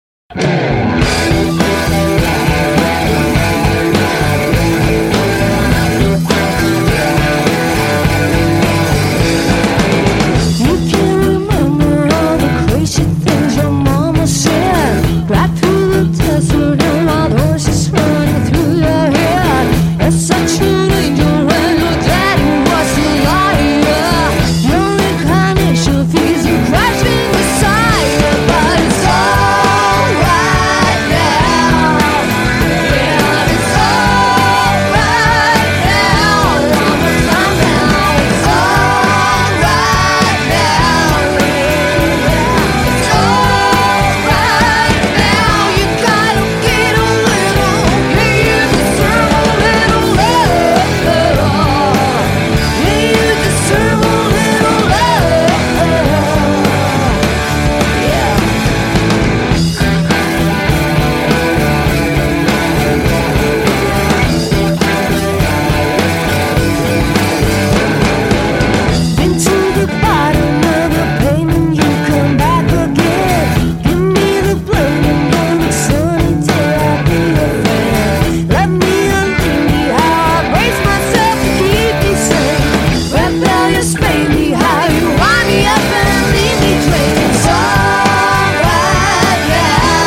sång
trummor
elgitarr, kör
bas, mandolin, wurlitzer